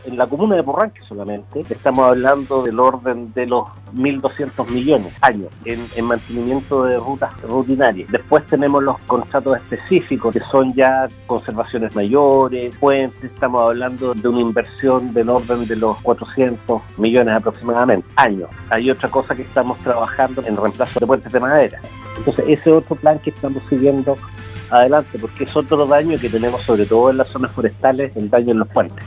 Por su parte, el director regional de Vialidad Los Lagos, Enzo Dellarossa, explicó en entrevista con Radio Sago que, por ejemplo, en Purranque, hay caminos que son deteriorados por las forestales y sus camiones de carga,a quienes pidieron que utilicen alternativas.